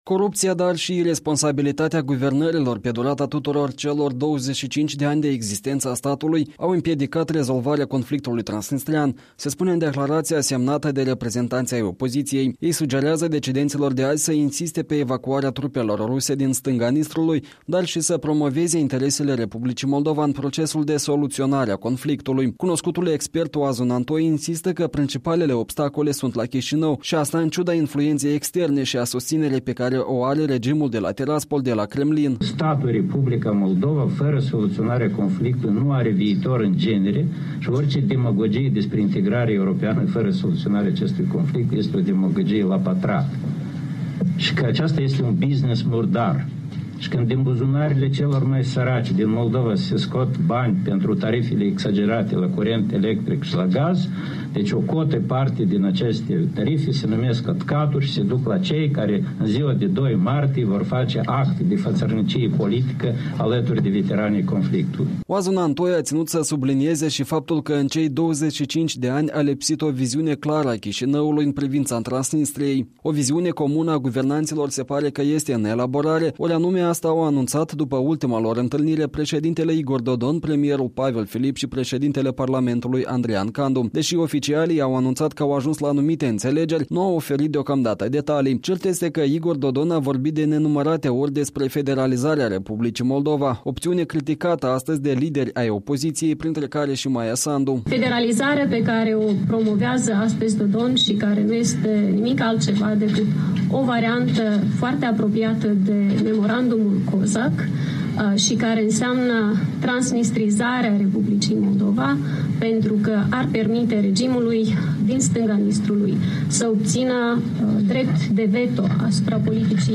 Politicienii opoziției de centru-dreapta l-au criticat astăzi pe președintele Igor Dodon și majoritatea parlamentară condusă de democratul Vlad Plahotniuc deopotrivă pentru lipsă de viziune sau viziune greșită în privința regiunii transnistrene. Maia Sandu, Andrei Năstase, Oazu Nantoi și Viorel Cibotaru le-au vorbit jurnaliștilor la o conferință de presă comună înainte de împlinirea a 25 de ani de la declanșarea conflictului de pe Nistru, pe 2 martie 1992.
Pavel Filip la ceremonia de comemorare